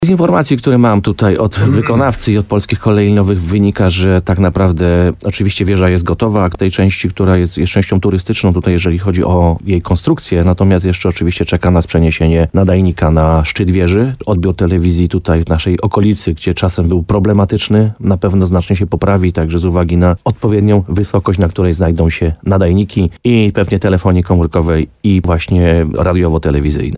– To będzie dwa w jednym – komentuje burmistrz Krynicy-Zdroju, Piotr Ryba.